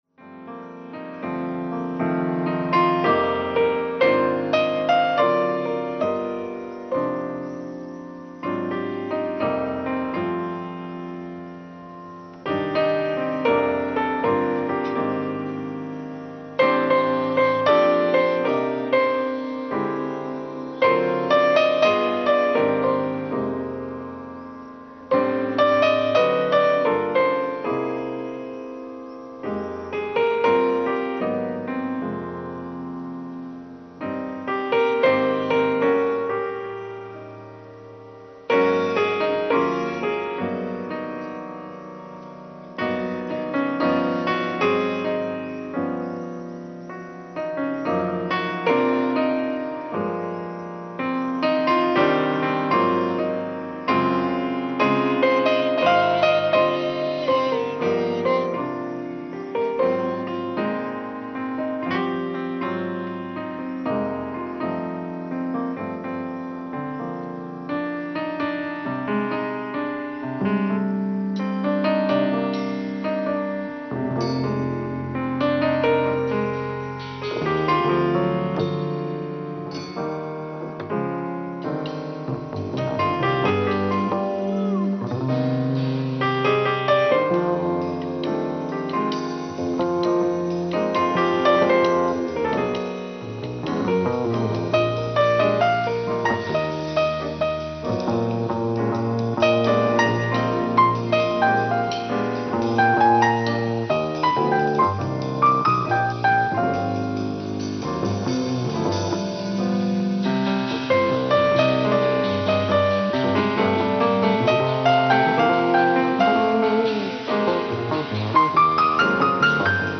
ライブ・アット・アンティーブ・ジャズ、ジュアン・レ・パン、フランス 07/26/1986
※試聴用に実際より音質を落としています。